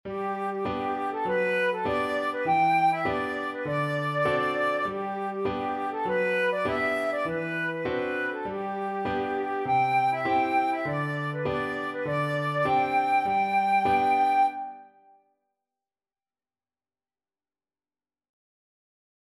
2/4 (View more 2/4 Music)
G5-G6
Playfully =c.100
Flute  (View more Easy Flute Music)